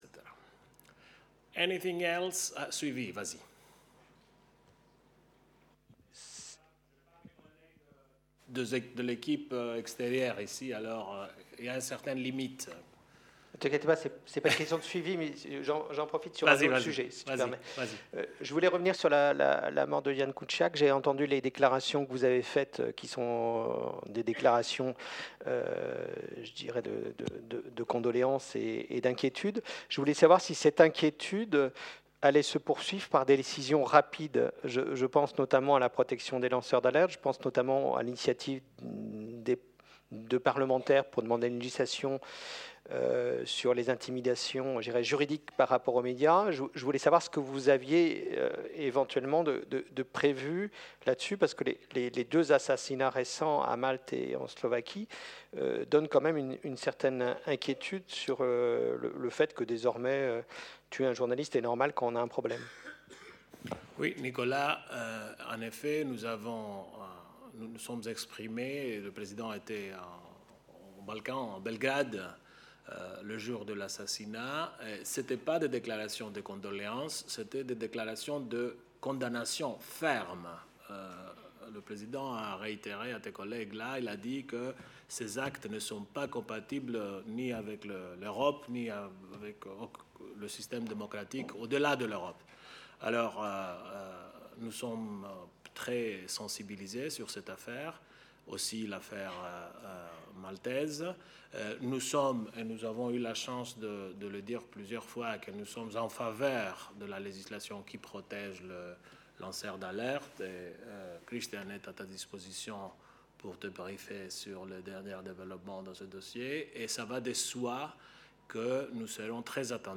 Extrait du point de presse du 6 mars 2018